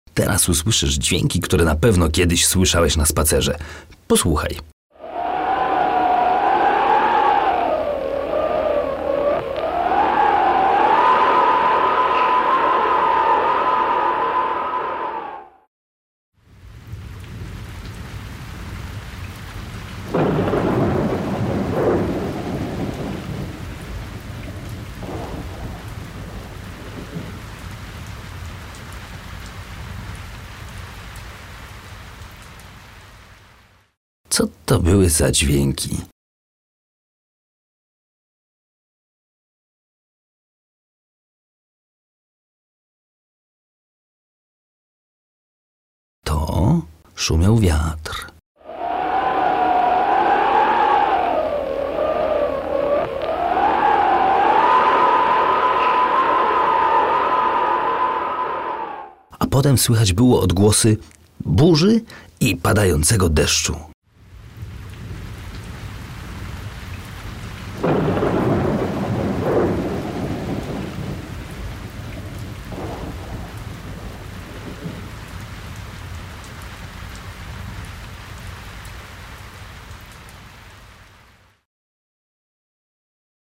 Teraz-uslyszysz-dzwieki...wiatr-deszcz.mp3